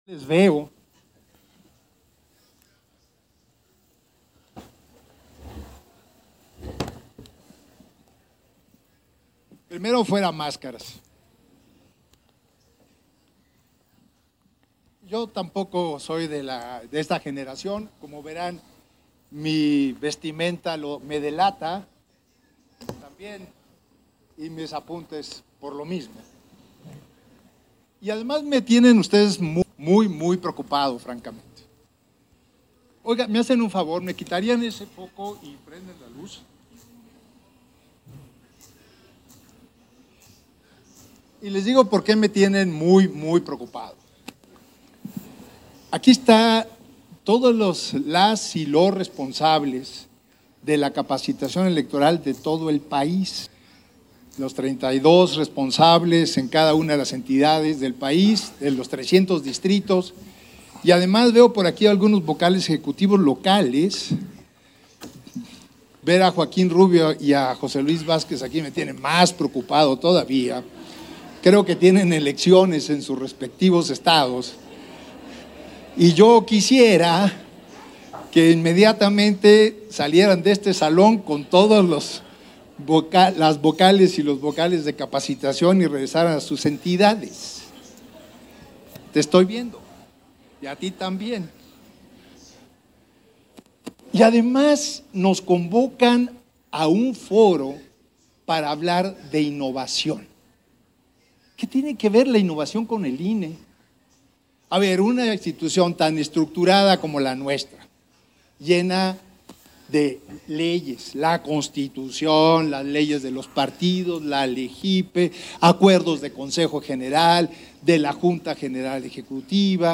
Intervención de Edmundo Jacobo Molina, en el Laboratorio de Innovación de la Estrategia de Capacitación y Asistencia Electoral